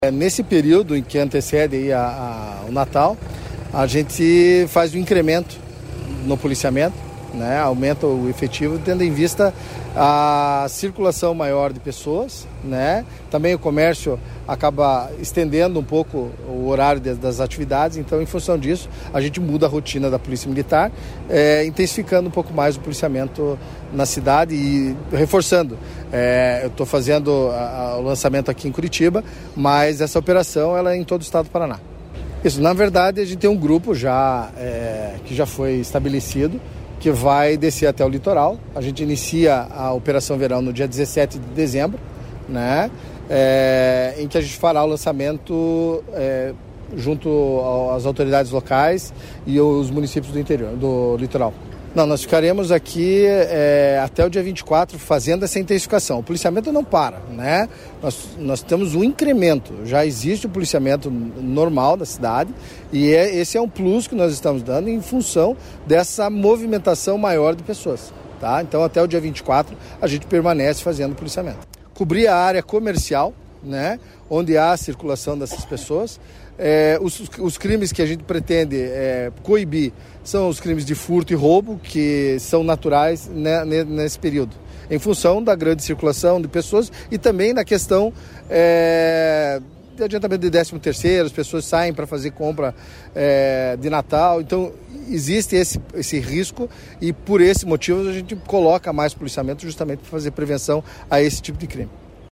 Sonora do subcomandante-geral da PMPR, coronel Carlos Eduardo Cidreira, sobre a Operação Natal